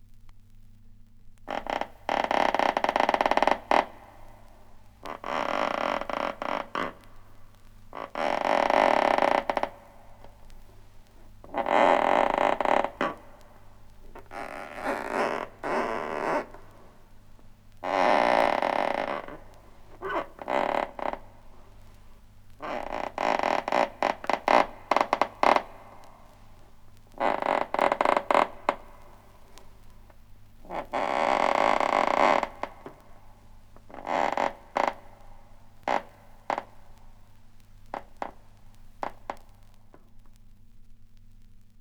• multiple assorted creepy creaks.wav
Recorded from Sound Effects - Death and Horror rare BBC records and tapes vinyl, vol. 13, 1977.
multiple_assorted_creepy_creaks_52X.wav